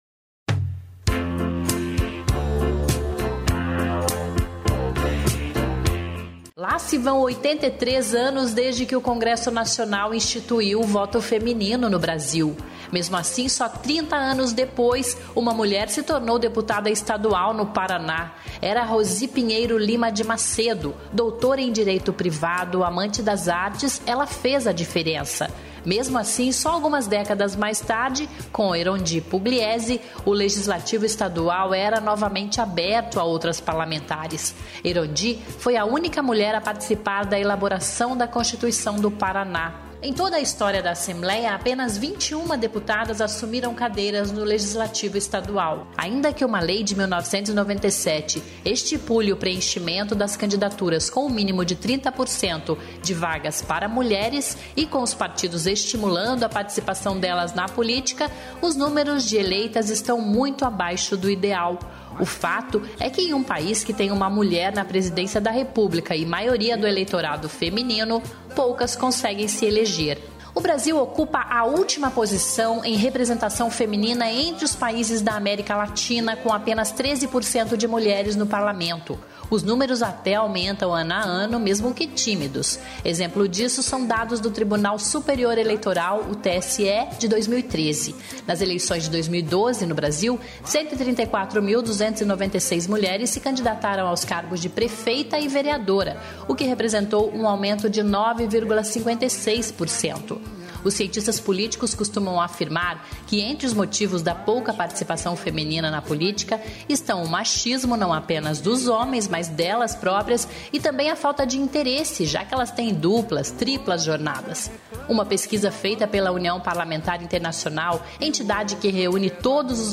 Reportagem especial: Dia Internacional da Mulher